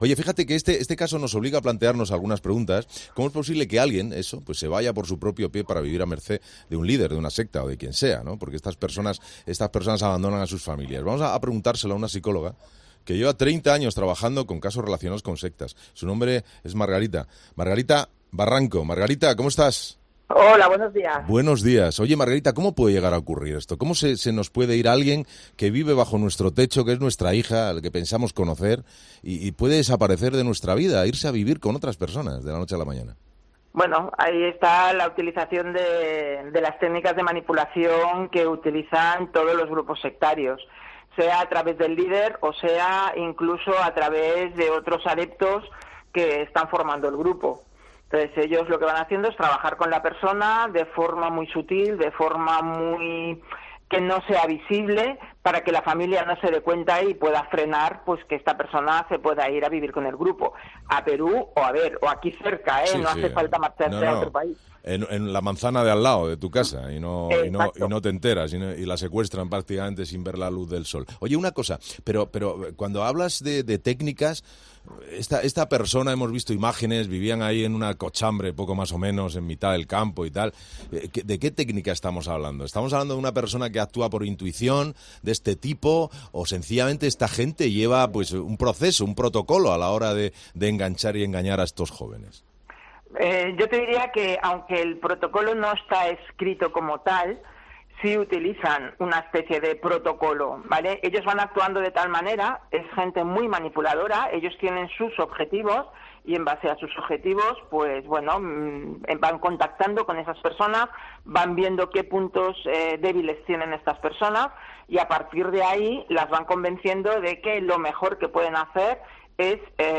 psicóloga y experta en sectas